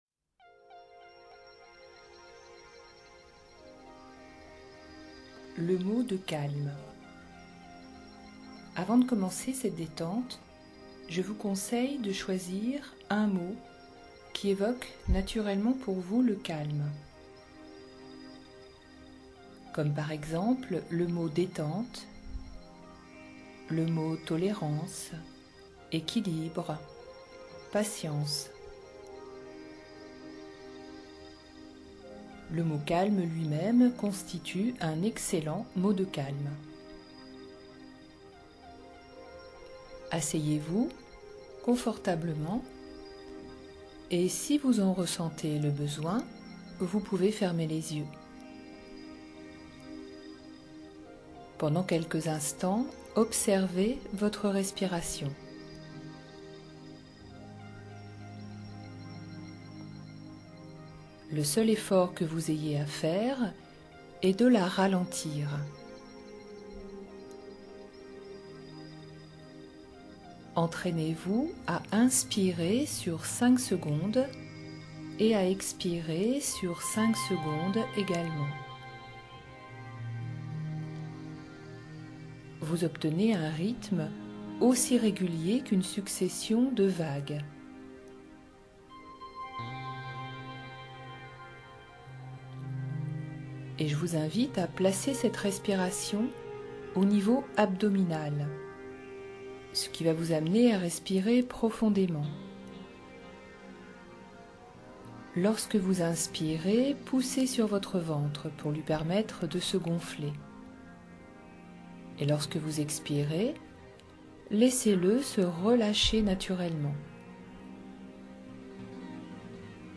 Pour vous permettre de bien débuter, voici un exercice de détente enregistré.
Musique : Dom Brandt - Spiritual Vortex - Lament for the earth